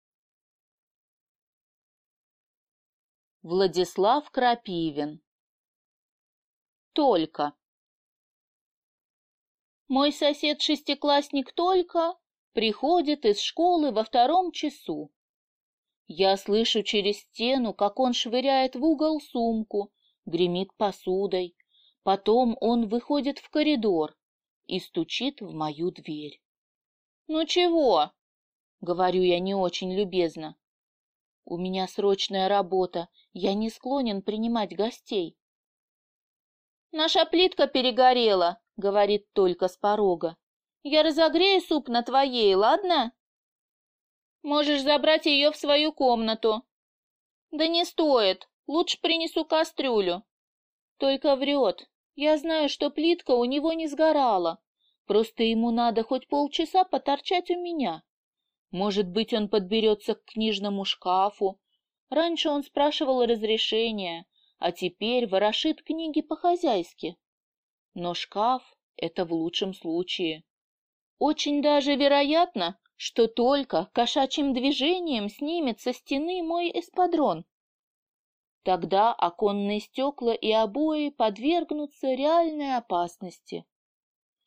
Аудиокнига Толька | Библиотека аудиокниг